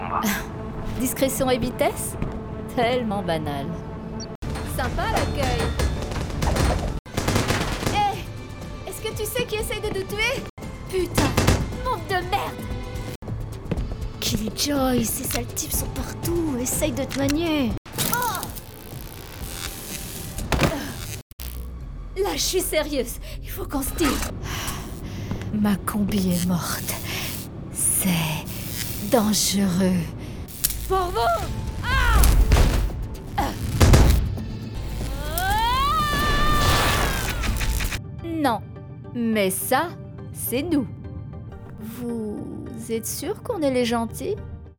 documentaire voix narrative jeune